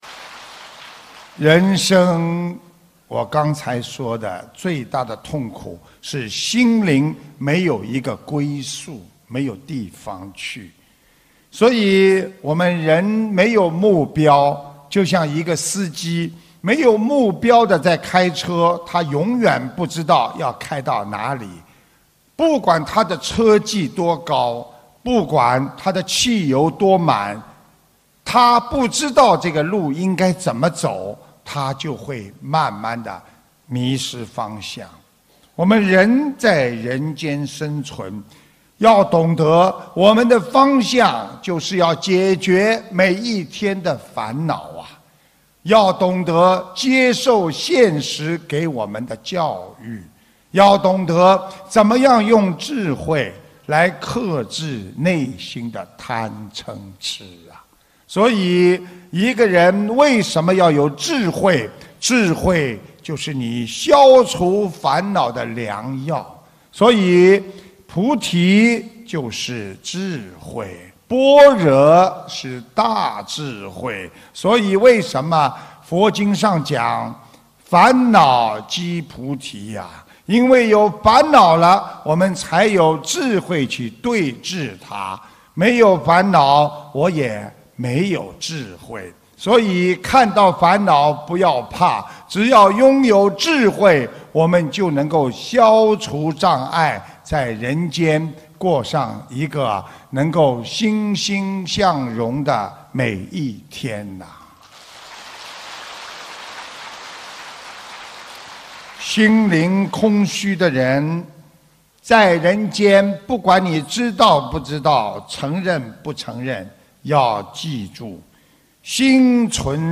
首页 >>菩提慧光 >> 法会小视频